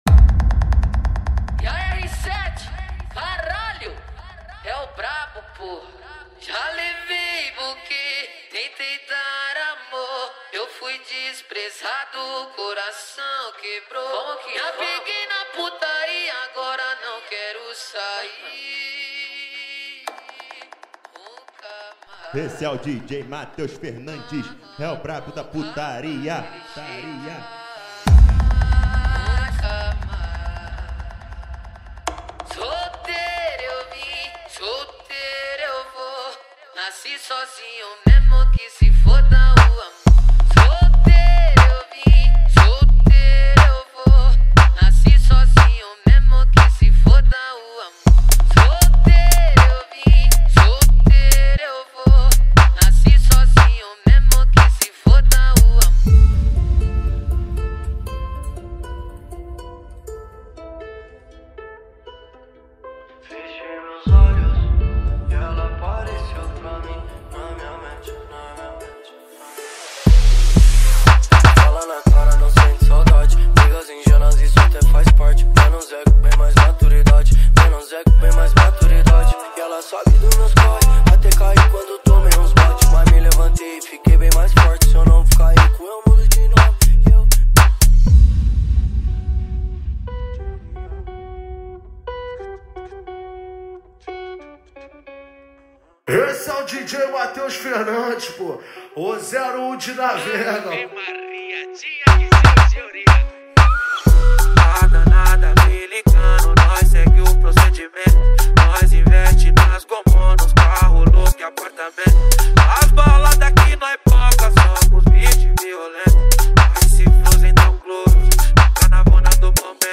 2024-10-07 09:37:45 Gênero: Funk Views